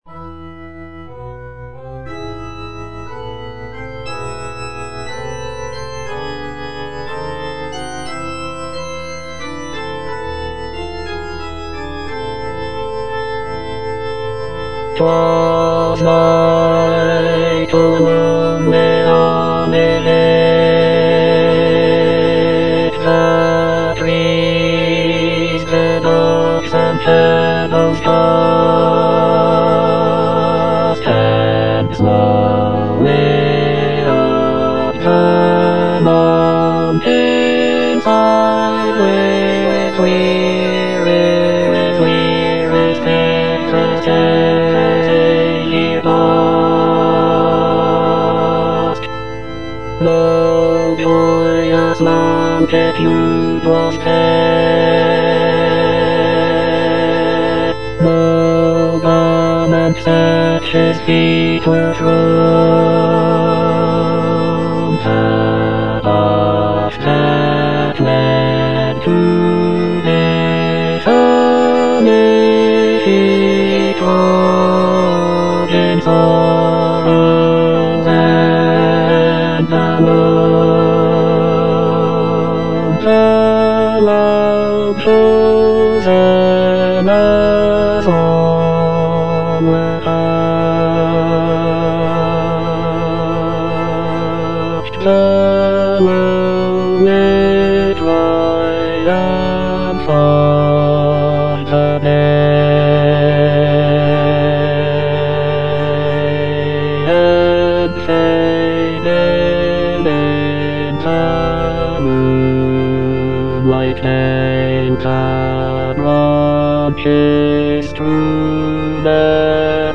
J.H. MAUNDER - OLIVET TO CALVARY 4a. The Mount of Olives (bass II) (Emphasised voice and other voices) Ads stop: auto-stop
"Olivet to Calvary" is a sacred cantata composed by John Henry Maunder in 1904. It presents a musical narrative of the events leading up to the crucifixion of Jesus Christ.